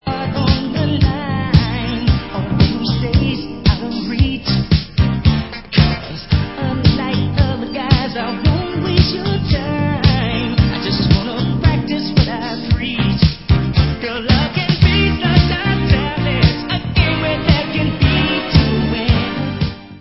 A COLLECTION OF FEEL-GOOD ANTHEMS OF SUMMER